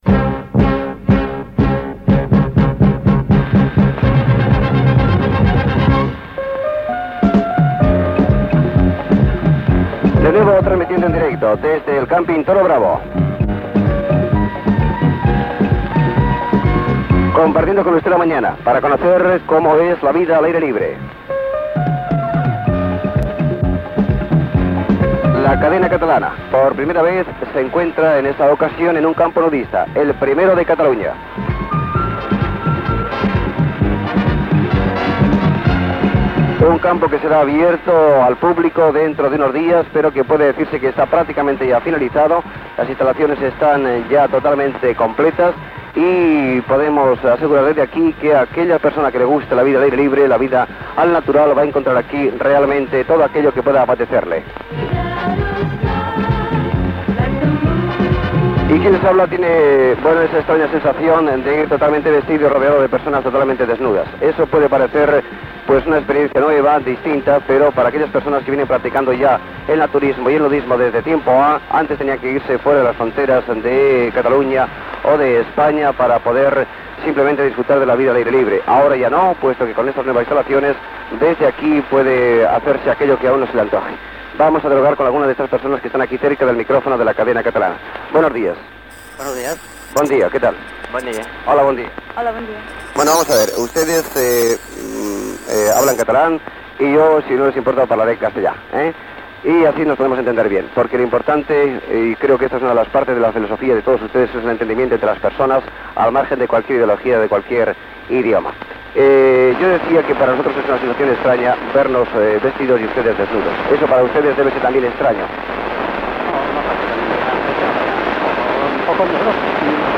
Emissió en directe des del primer càmping nudista de Catalunya, El Toro Bravo, de Viladecans. Entrevista a algunes perones que fan nudisme, amb algunes interferències del senyal.
Entreteniment